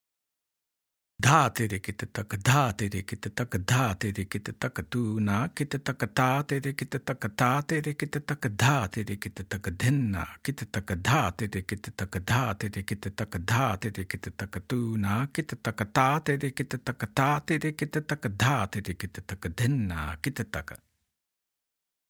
2x Speed – Spoken